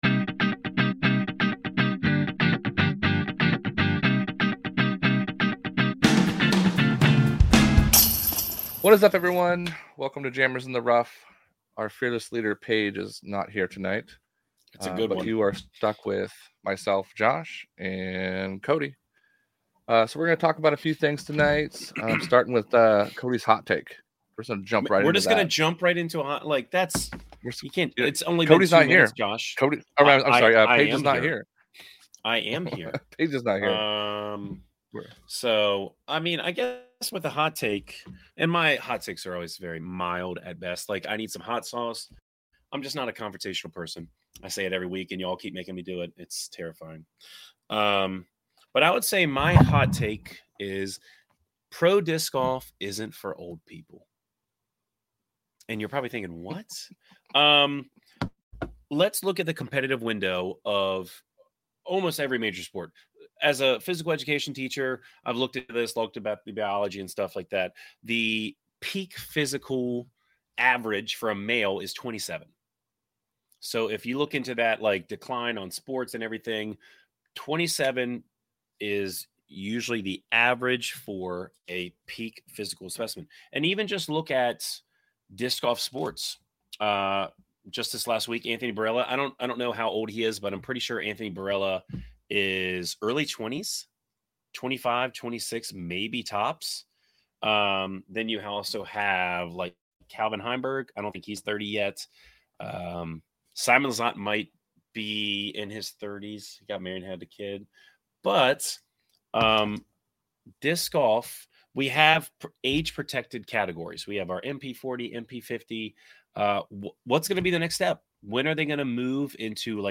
This is recorded live and raw in one take. With special guests, news, disc reviews, and other disc golf topics.